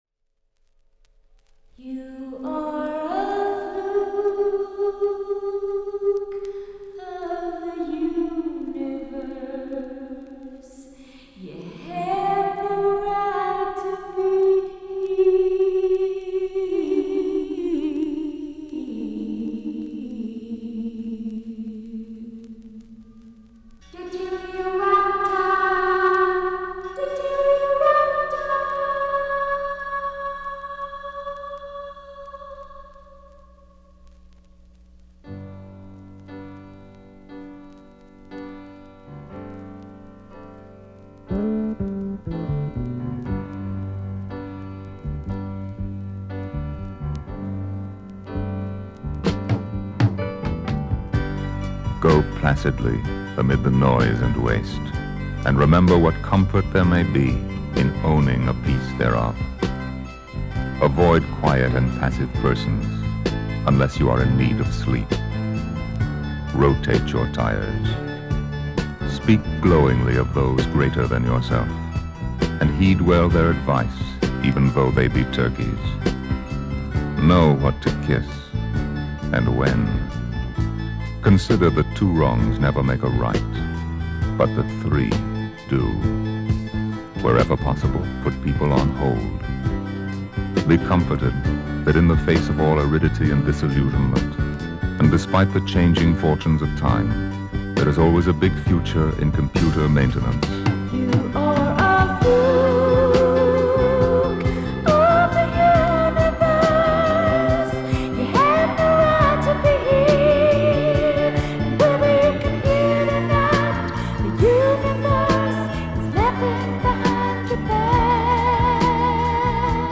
satirical spoken poem